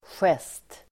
Uttal: [sjes:t]